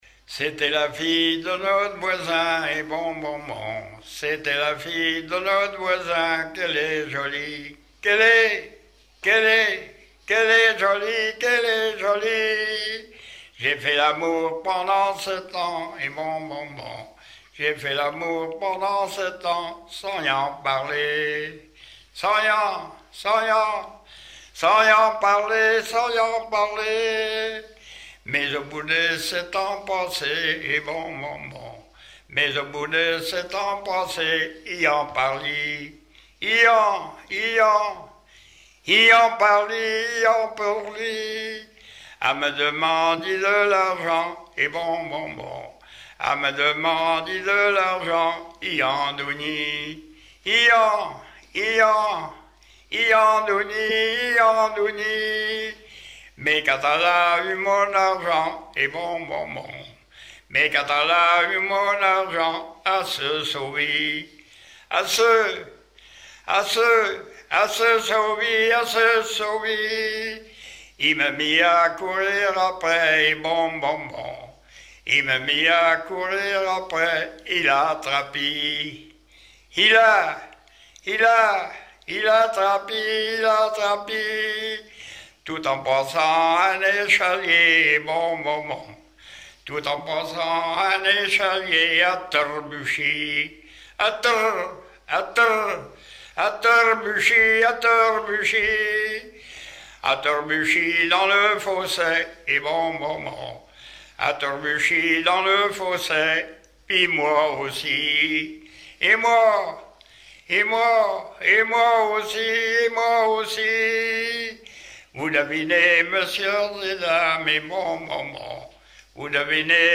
Patois local
Genre laisse